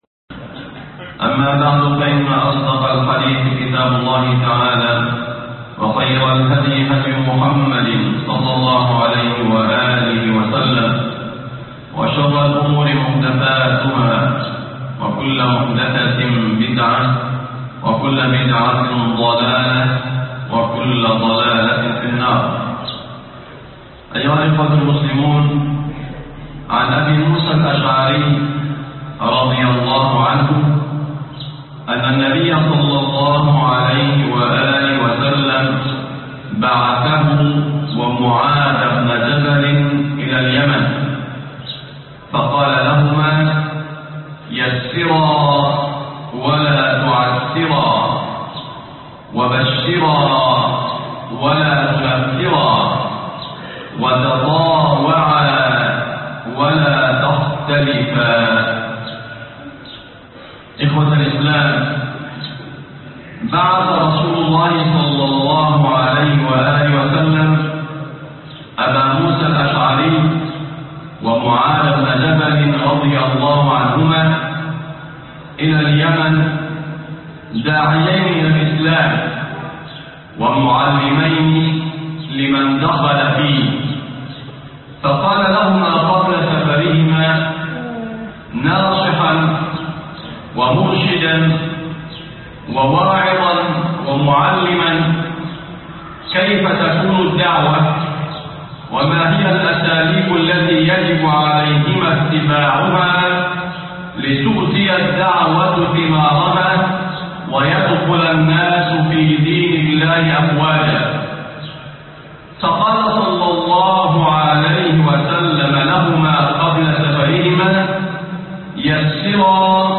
سماحة الإسلام ويسر الدين - خطب الجمعة